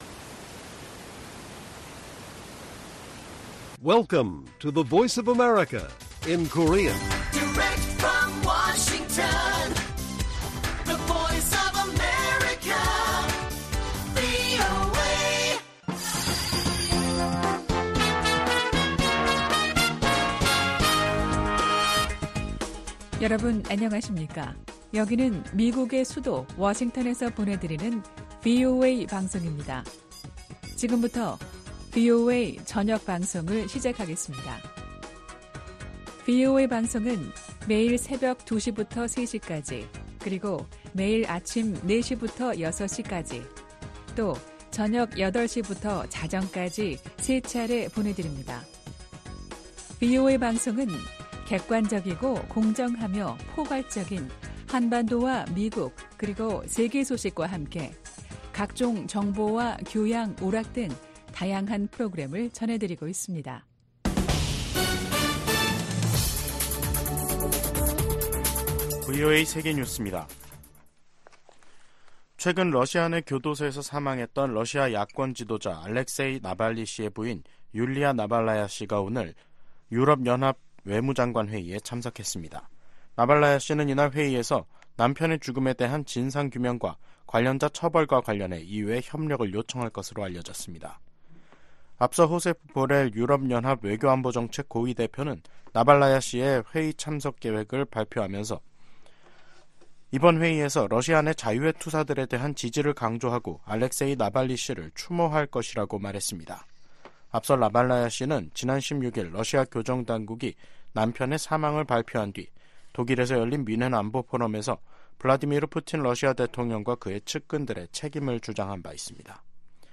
VOA 한국어 간판 뉴스 프로그램 '뉴스 투데이', 2024년 2월 19일 1부 방송입니다. 미 국무부는 러시아의 북한산 탄도미사일 사용 사실을 확인하고 모든 수단을 동원해 북-러 무기 거래를 막을 것이라고 강조했습니다. 백악관은 북한-일본 정상회담 추진 가능성에 대해 지지 입장을 밝히고 미한일 협력에 균열 우려는 없다고 밝혔습니다. 북한이 핵·미사일 역량을 키우면서 미국·동맹에 위협 수준이 점증하고 있다고 미 전략사령관이 지적했습니다.